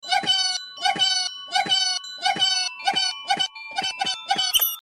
YIPPEE 🎉😁 sound effects free download